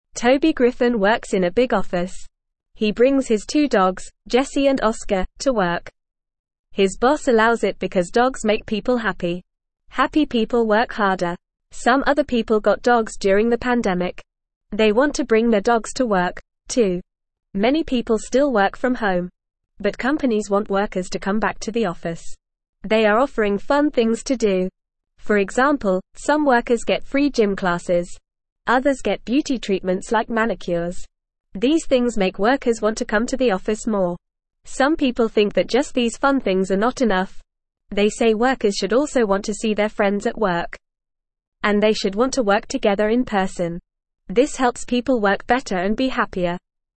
Fast
English-Newsroom-Beginner-FAST-Reading-Dogs-and-Fun-Activities-in-Office.mp3